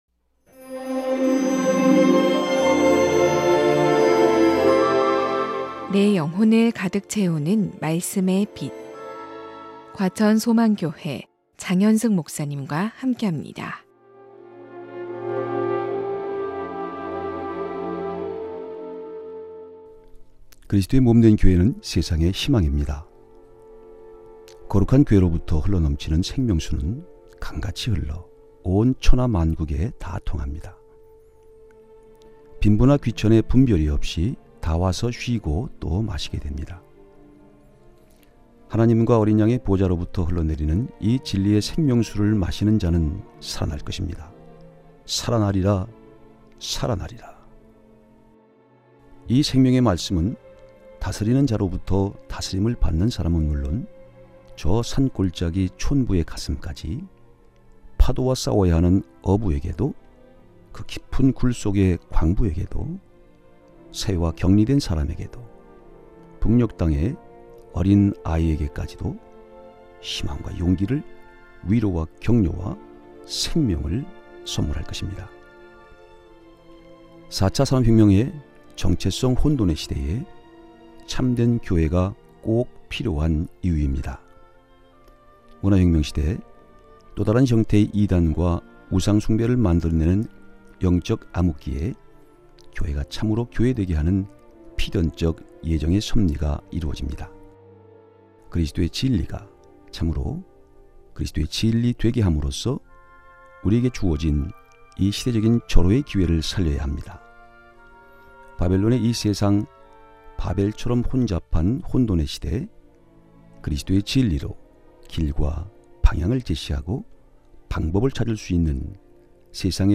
극동방송 설교 녹음파일 모아 듣기